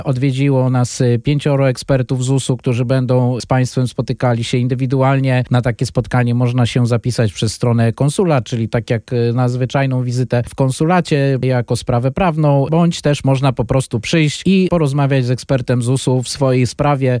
W studiu Radia Deon Konsul Michał Arciszewski serdecznie zaprasza na Dni Poradnictwa ZUS w Chicago, które odbędą się w dniach 15-17 grudnia 2023 r. w siedzibie Związku Narodowego Polskiego (PNA) przy 6100 N Cicero Ave.